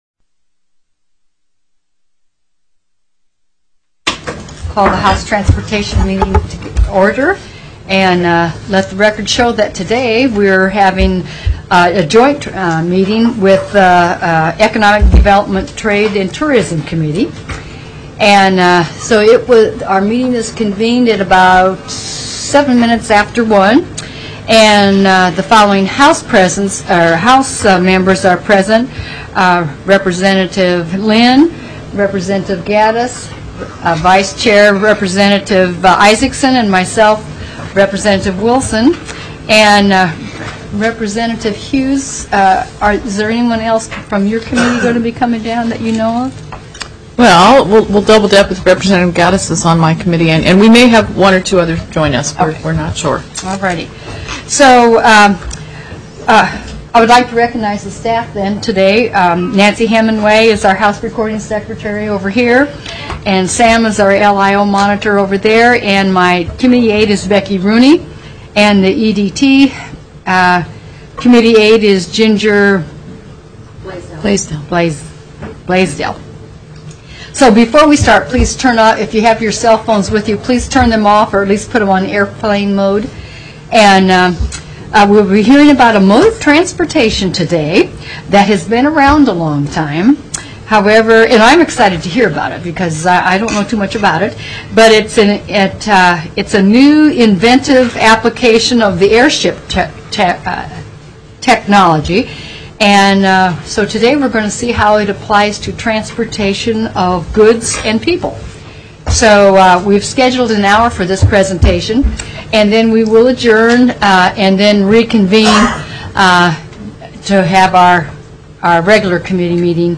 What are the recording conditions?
ALASKA STATE LEGISLATURE JOINT MEETING HOUSE TRANSPORTATION STANDING COMMITTEE HOUSE SPECIAL COMMITTEE ON ECONOMIC DEVELOPMENT, TRADE, AND TOURISM